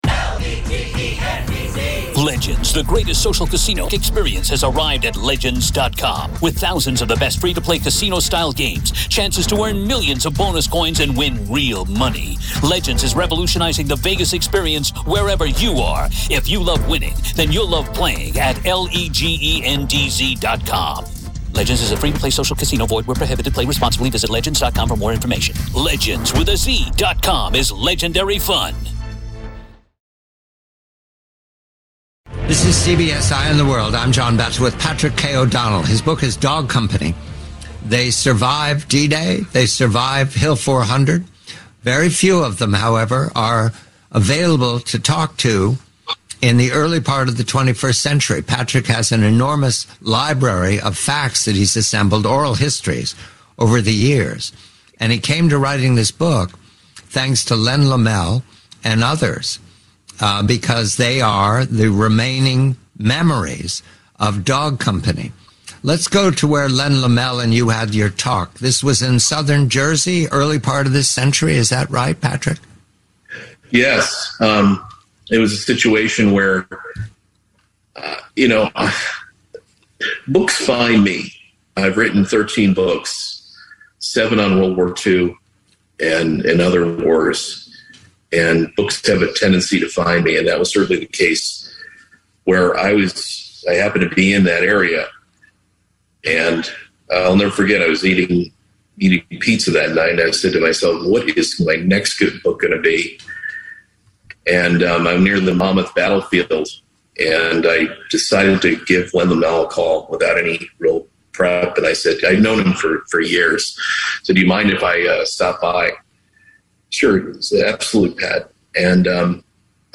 Audible Audiobook – Unabridged